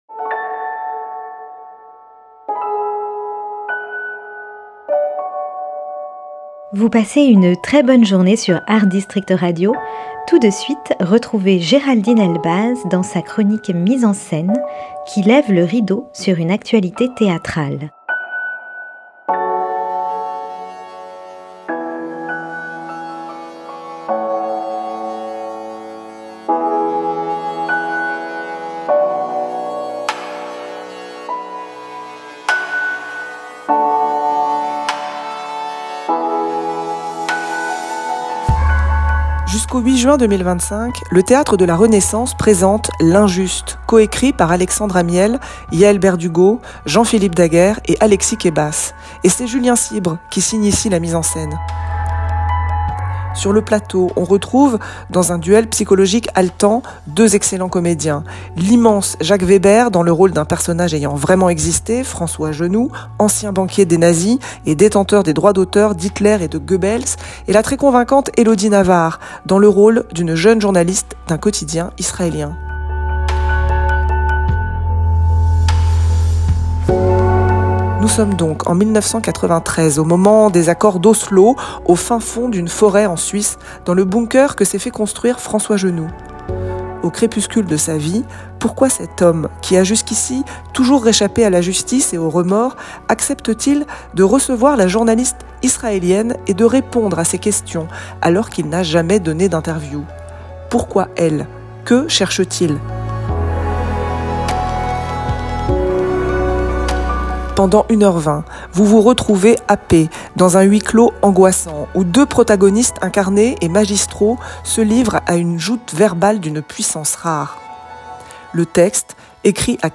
MISES EN SCENE le mercredi et vendredi à 9h30 et 18h30. Chronique théâtrale